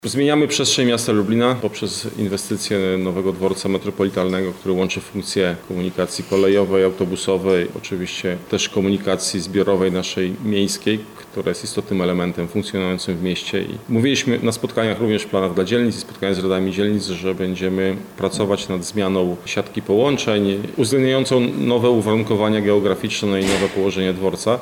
Artur Szymczyk– mówi Artur Szymczyk, Zastępca Prezydent Lublina, ds. Inwestycji i Rozwoju.